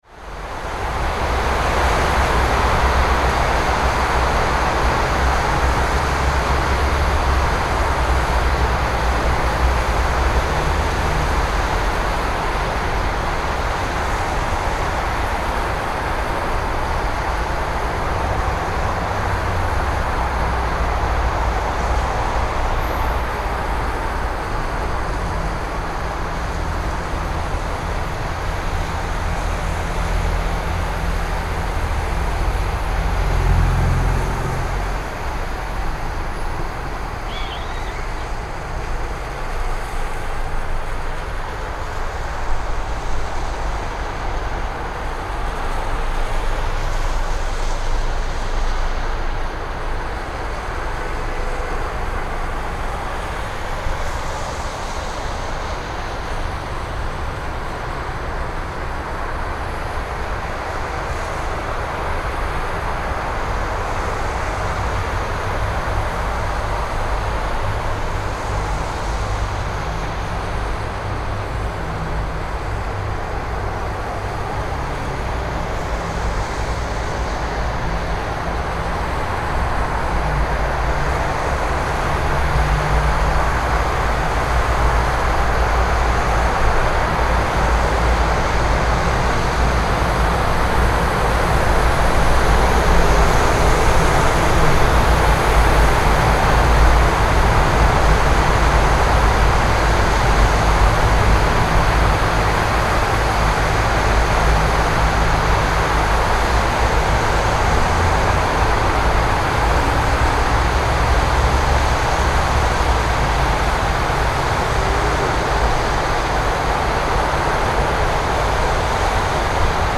Driving-on-wet-city-streets-sound-effect.mp3